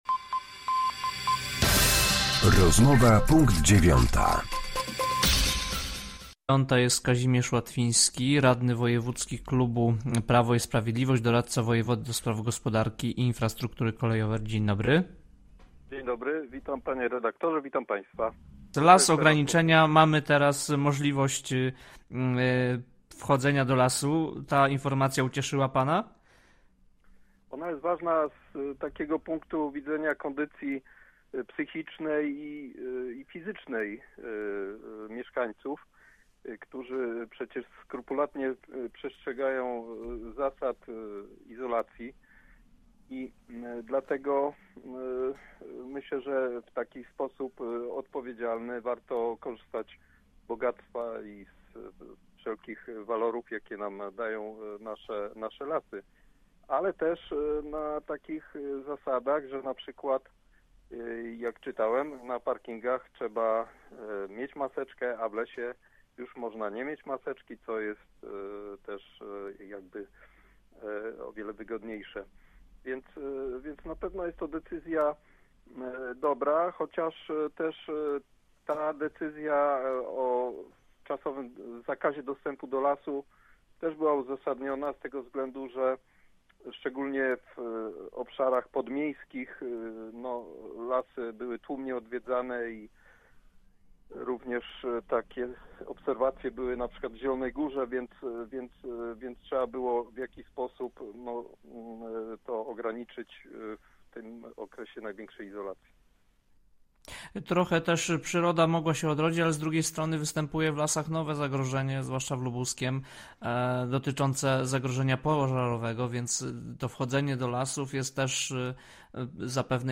Z radnym wojewódzkim klubu Prawo i Sprawiedliwość, doradcą wojewody ds. gospodarki i infrastruktury kolejowo-drogowej rozmawia